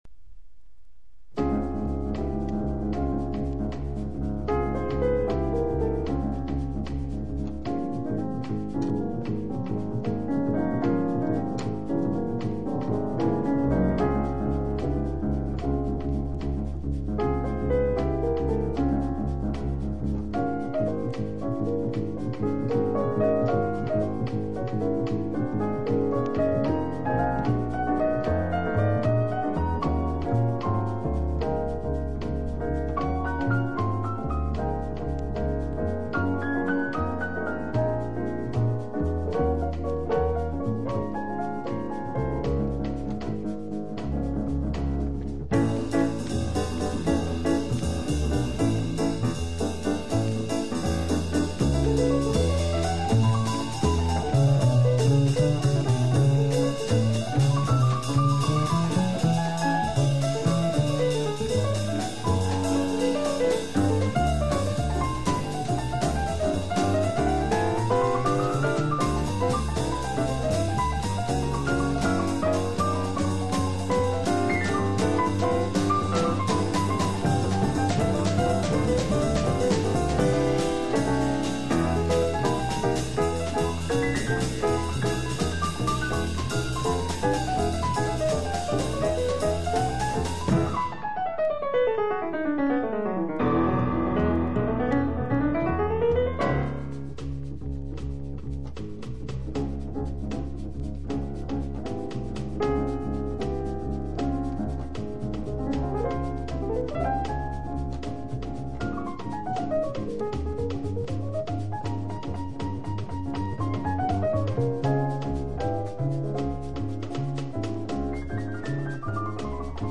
トリオもの好内容盤